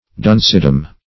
Duncedom \Dunce"dom\, n. The realm or domain of dunces.